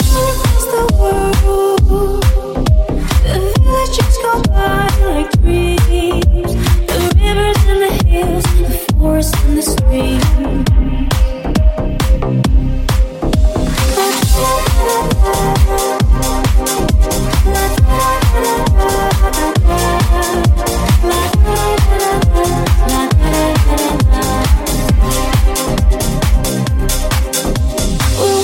Genere: edm,deep,bounce,house,slap,cover,remix hit